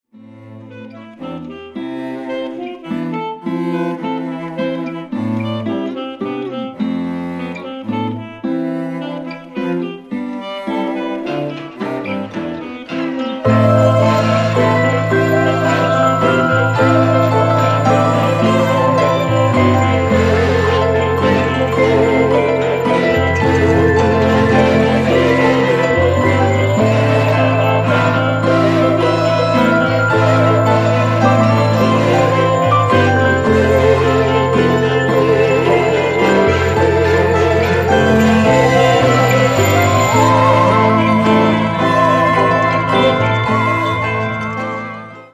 Entièrement acoustique